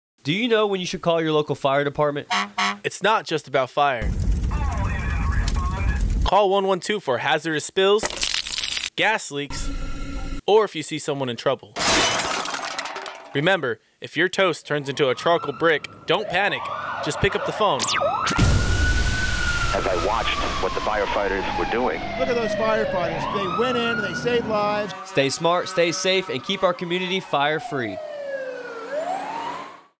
This radio spot is for information about when to call the Fire Department in the VMC area